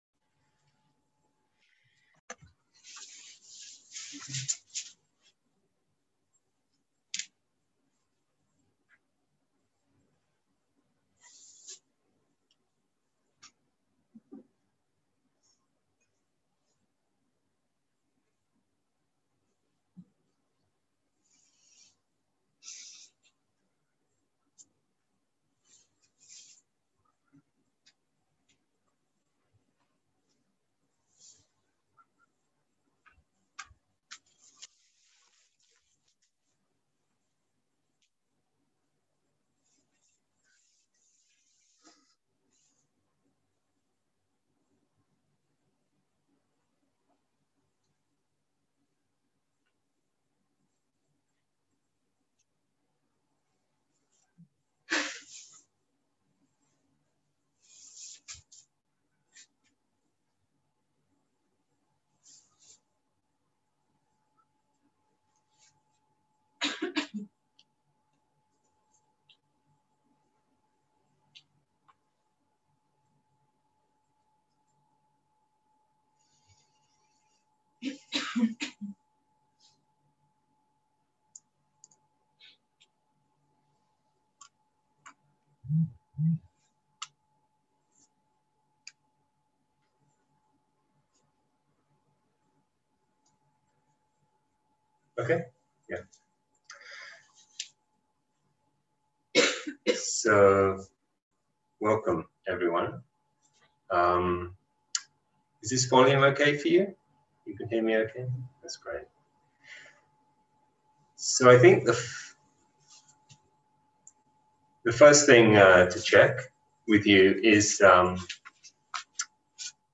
שיחת דהרמה - מטא וריקות
Dharma type: Dharma Talks